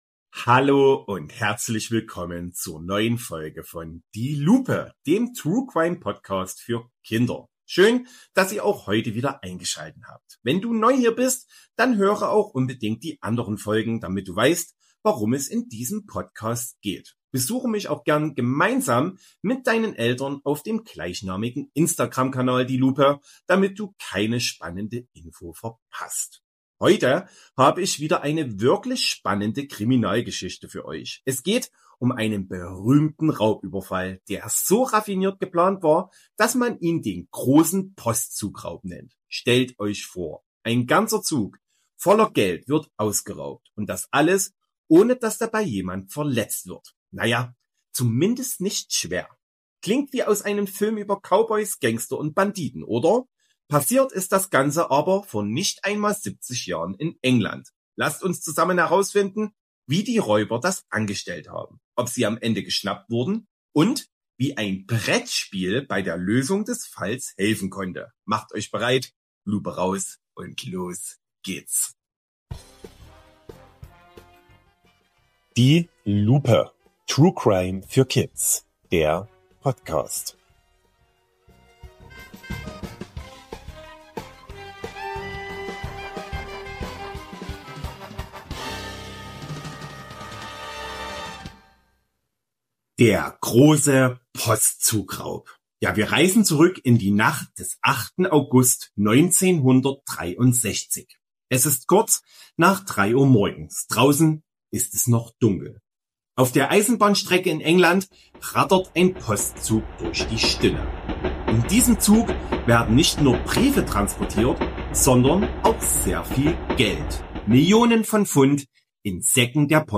Wie kam die Polizei den Räubern trotzdem auf die Spur? Ein echter Kriminalfall Spannende Ermittlungsarbeit Ruhig, verständlich und kindgerecht erzählt Mehr